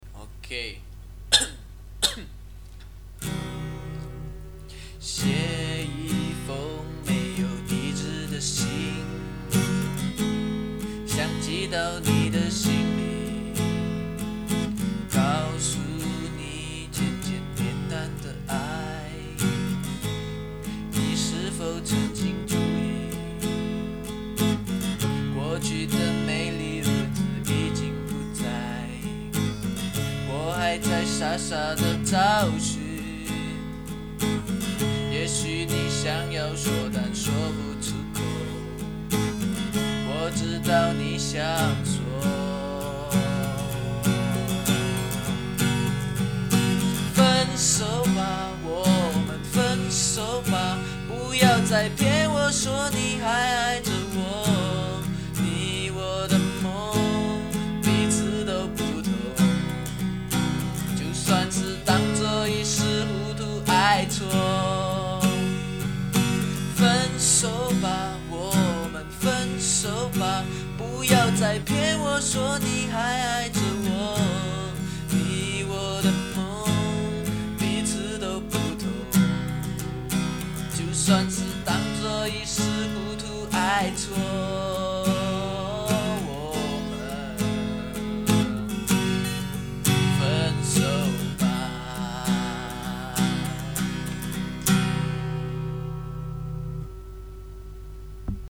爱情, 生活, 自弹自唱
唱两首歌大家听